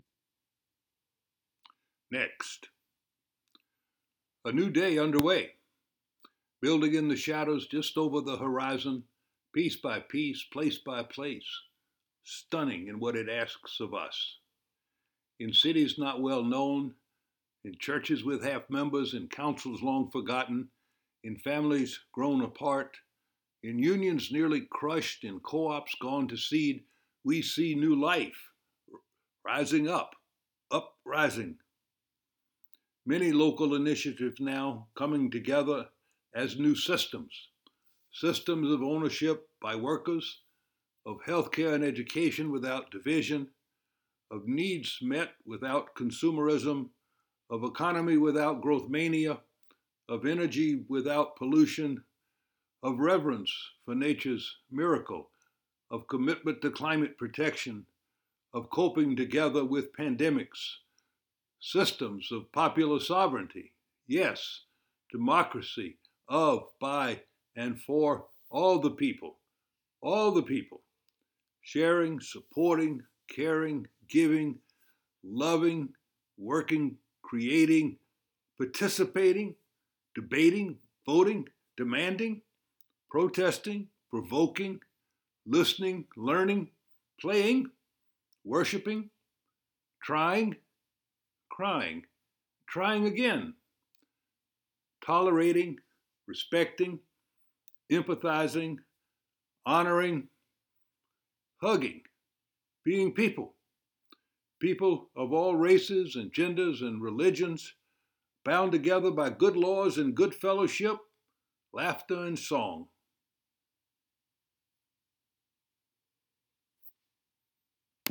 A poem by Gus Speth, first the recording of him reading it and below that the text (Gus shared this with us on Sunday, March 29th, in our Zoom Joys and Concerns gathering).